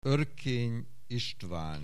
Aussprache Aussprache
OERKENYISTVAN.wav